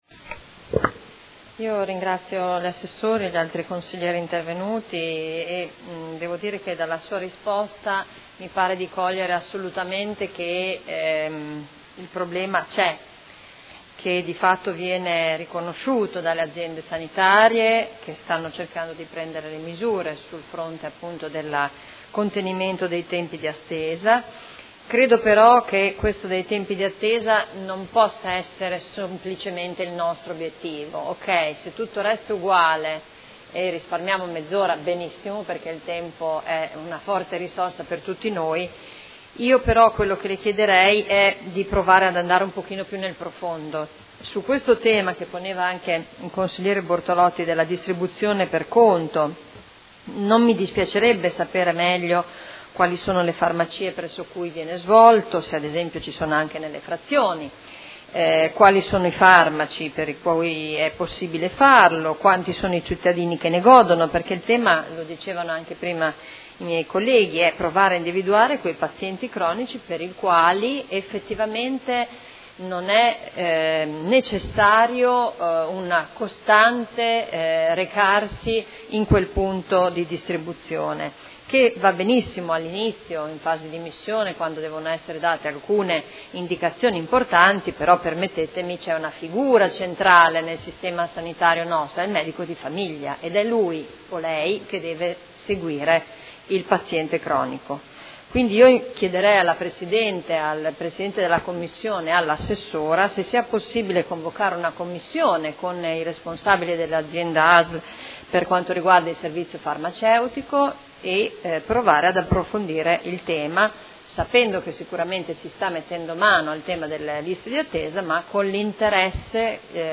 Seduta del 5/11/2015. Interrogazione delle Consigliere Arletti e Liotti (P.D.) avente per oggetto: Tempi di attesa alla Farmacia interna al Policlinico. Replica